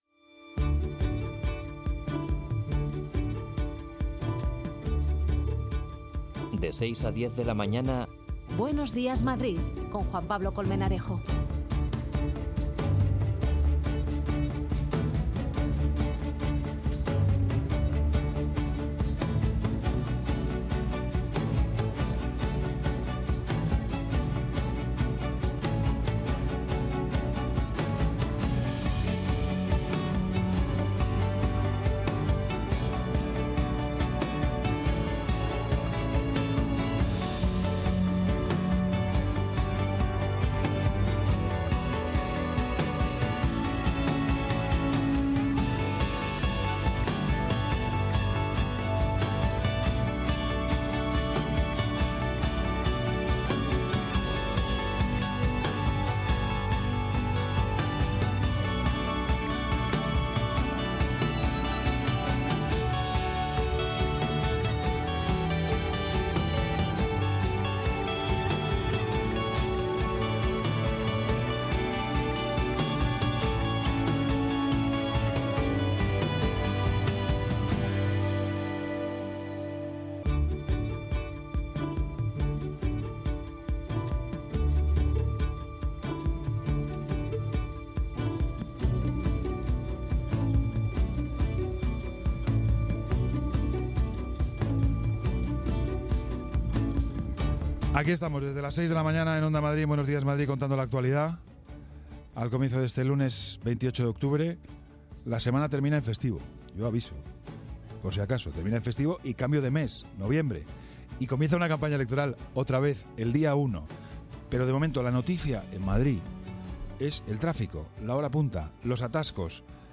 Entrevista a Paco Vázquez, exalcalde de La Coruña.